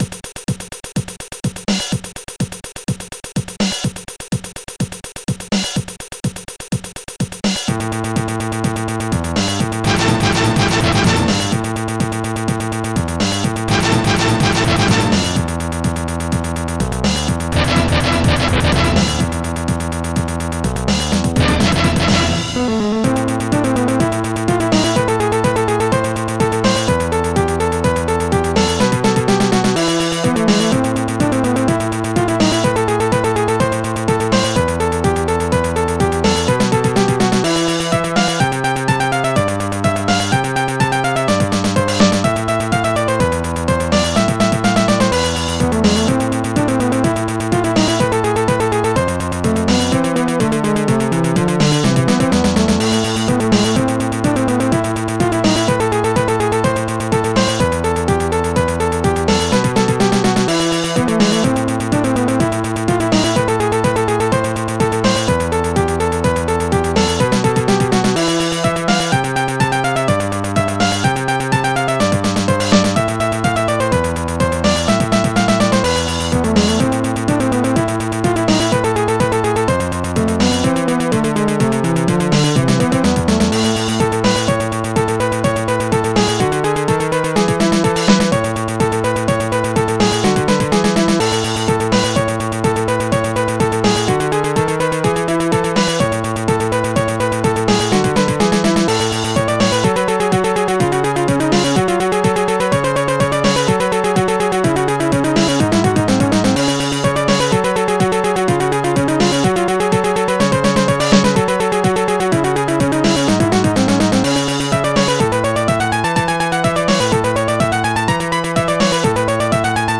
OctaMED Module